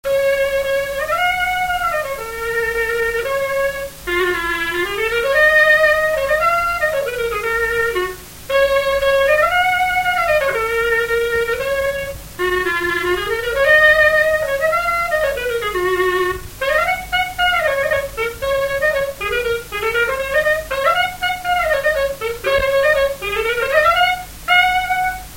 Marche nuptiale
Résumé instrumental
circonstance : fiançaille, noce
Pièce musicale inédite